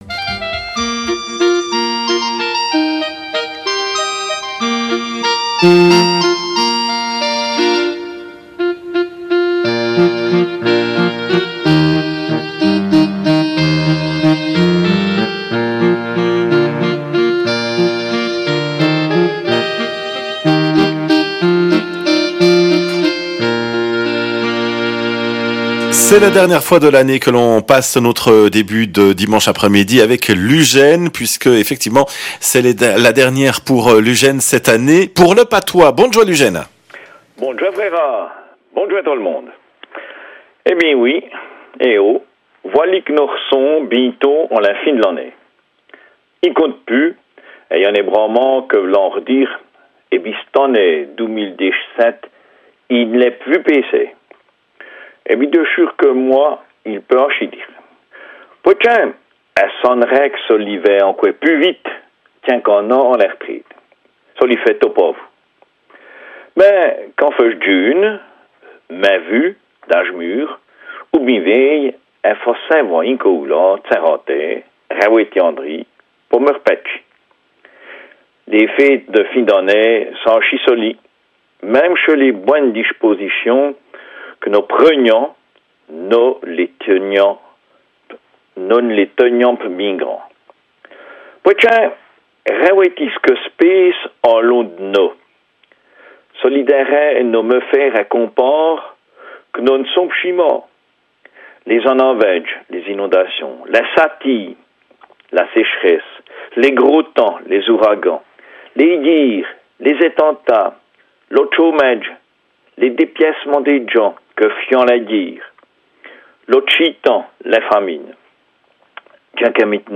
RFJ 11 décembre 2017 Radio Fréquence Jura RFJ Rubrique en patois du 11 décembre 2017